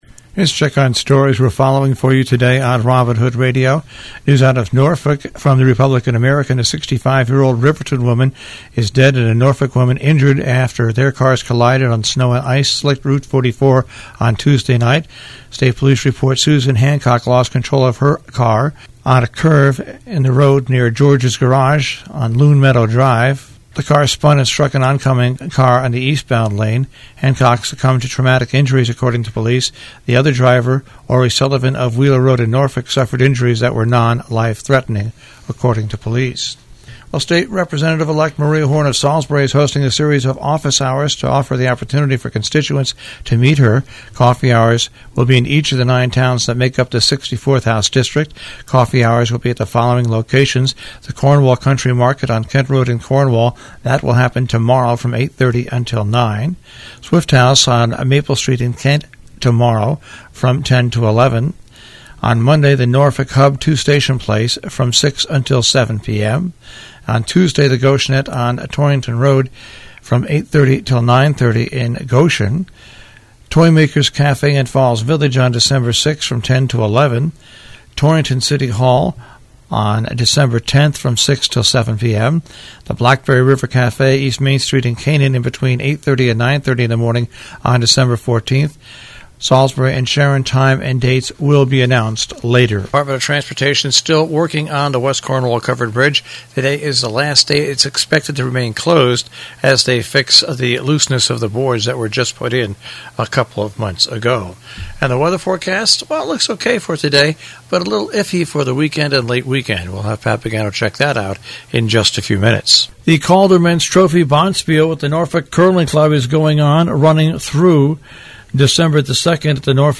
WHDD BREAKFAST CLUB NEWS - THURSDAY NOVEMBER 29